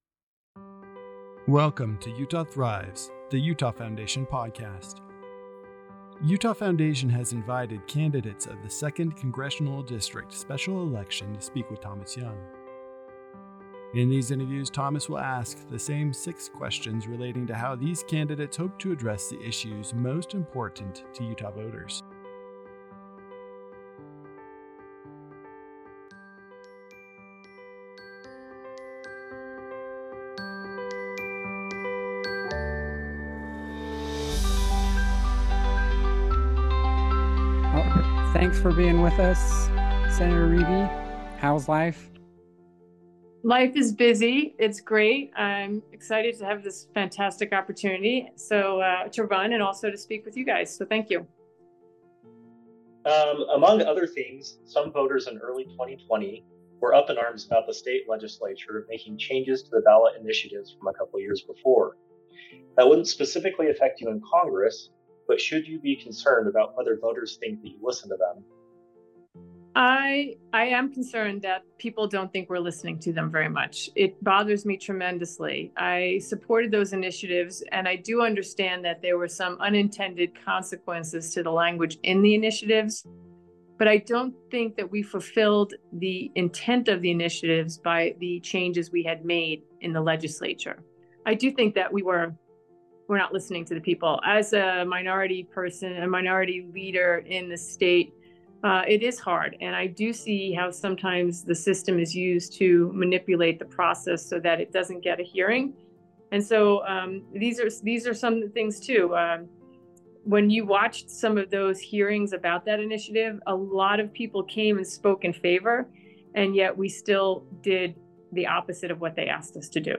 Utah Thrives Podcast | Interview with Kathleen Riebe - Utah Foundation
This podcast has been edited for brevity.